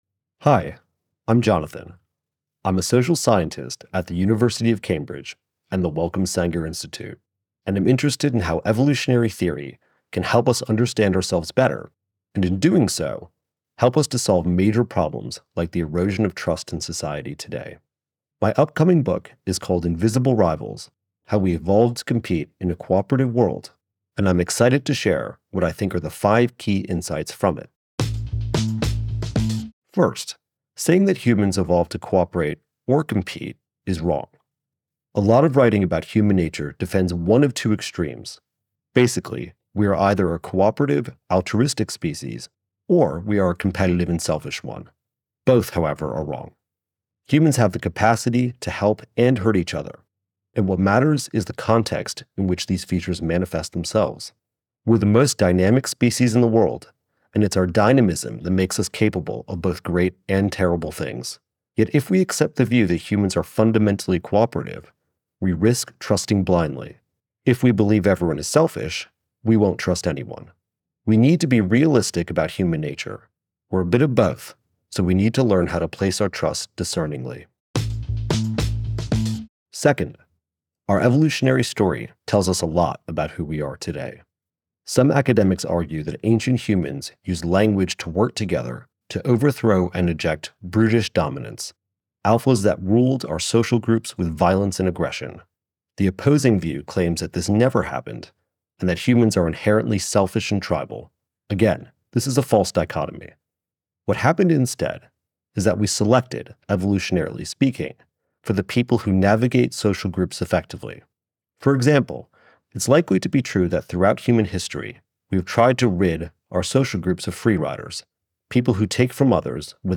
Enjoy our full library of Book Bites—read by the authors!—in the Next Big Idea App: